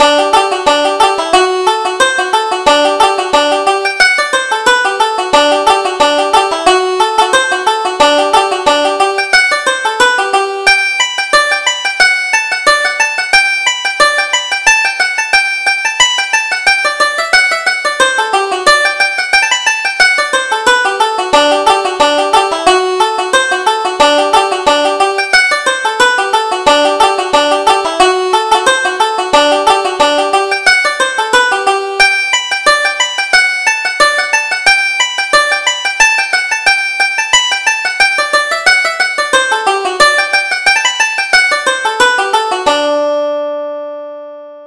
Reel: The Ewe Reel